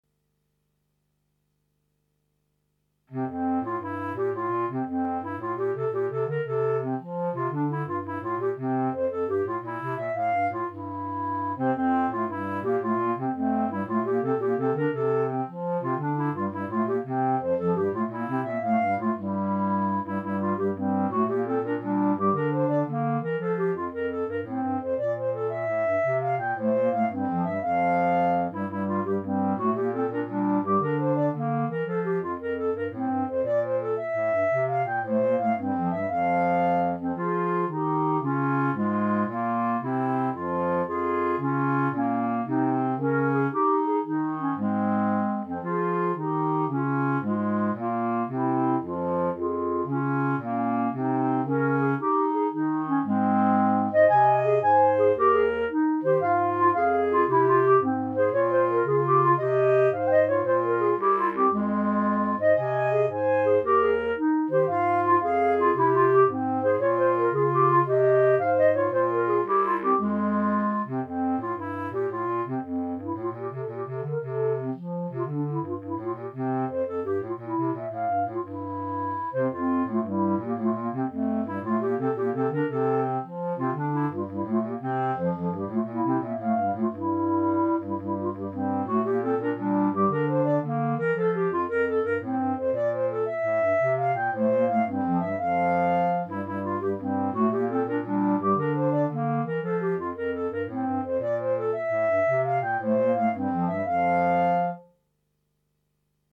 Right click to download Bourree minus Clarinet 1